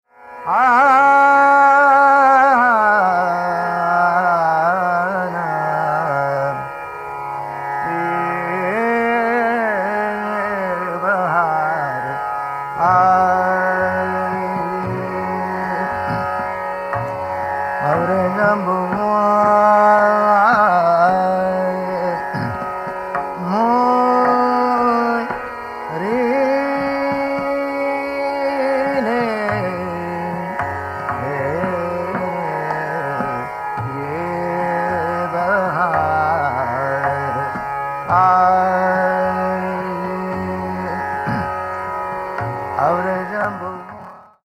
A double-Ga, double-Ni fusion of Rageshri and Bahar, with the former dominating the aural impression.
Jazz: Dorian-Ionian
• Tanpura: Sa–ma